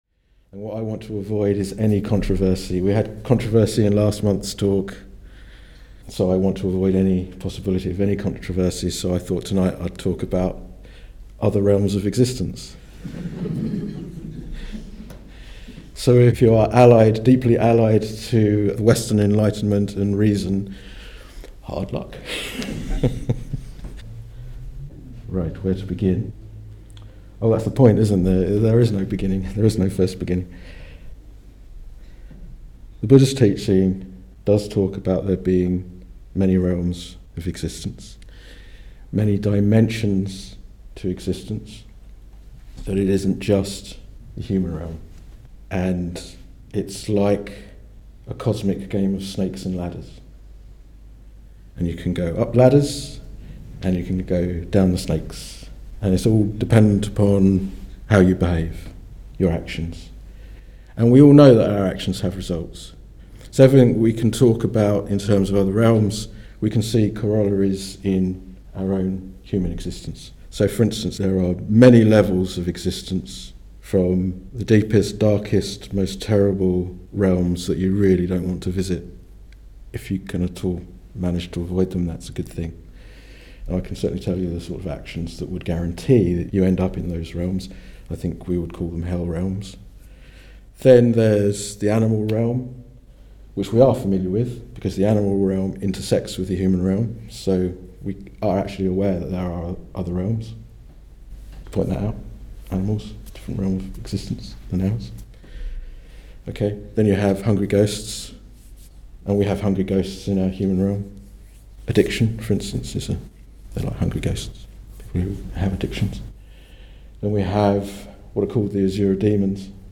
This talk was given in October 2019